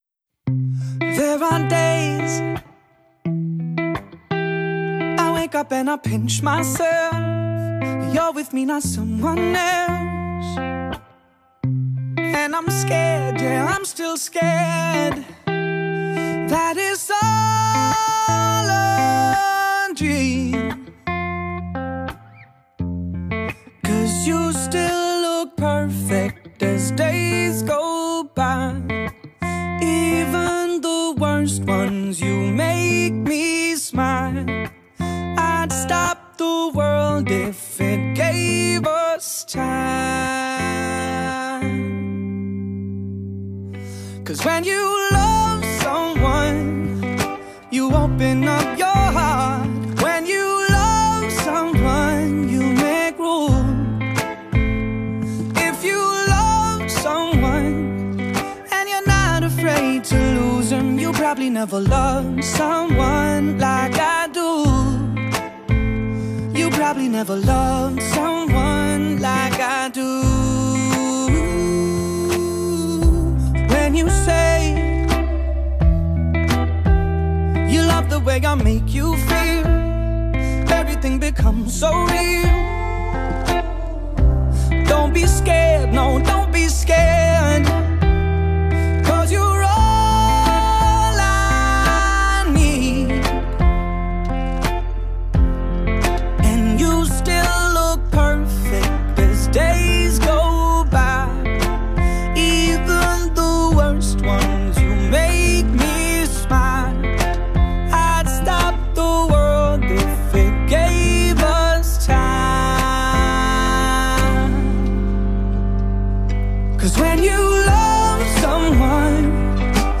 with Lead Vocals mp3